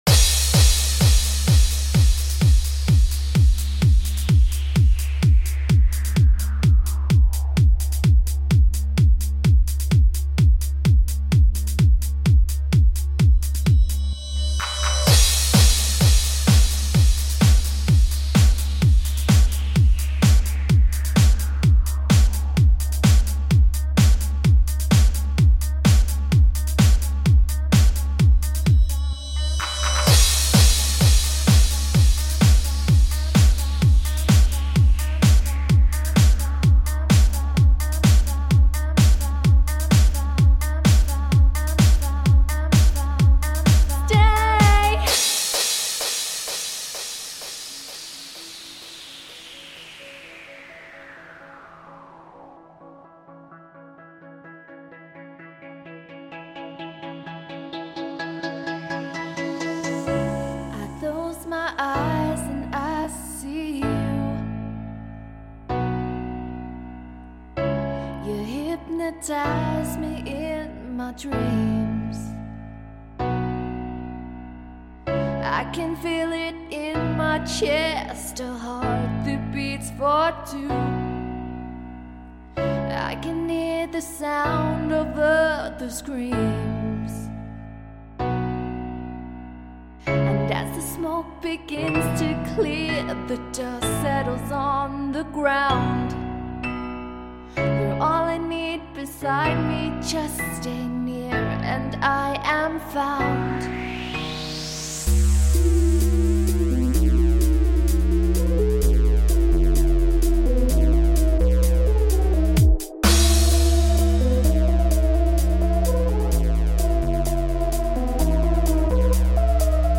Closest to it was recreating the piano part with a twist.